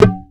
Bongo18.wav